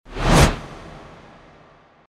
FX-618-WIPE
FX-618-WIPE.mp3